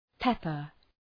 Προφορά
{‘pepər}